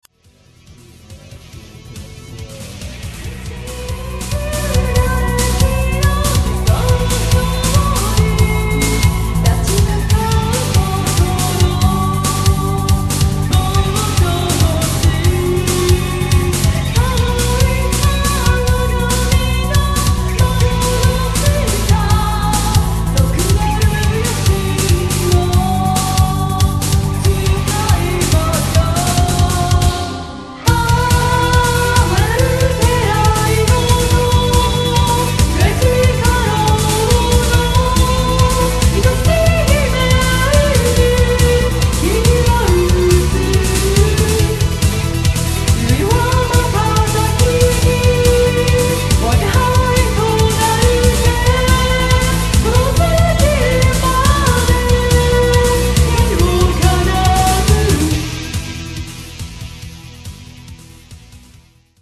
ビジュアル系バンド